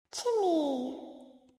Po wpadnięciu do kominka usłyszymy uroczy dźwięk, a na czacie pojawi się wiadomość: